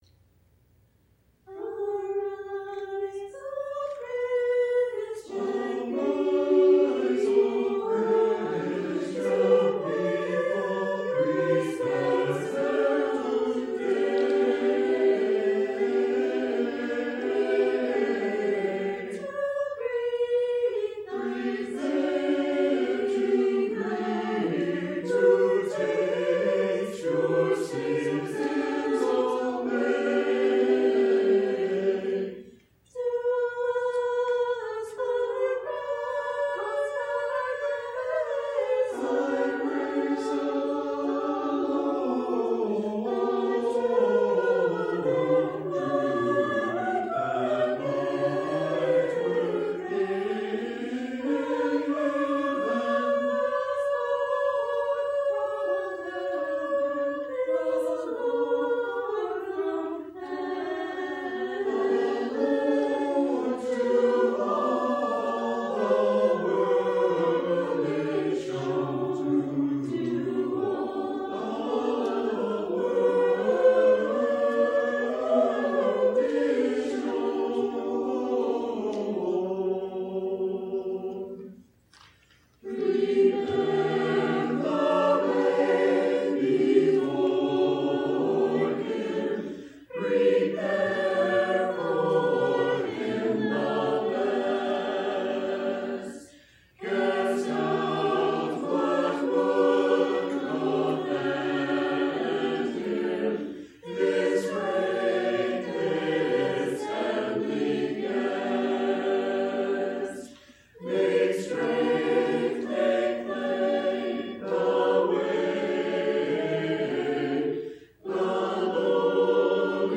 Senior Choir
Senior Choir Performances